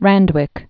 (răndwĭk)